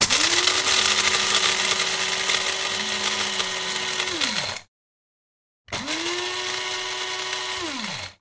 coffeegrinder
描述：Coffee grinder recorded with an sm57
标签： coffee grinder
声道立体声